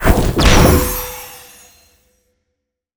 spell_harness_magic_02.wav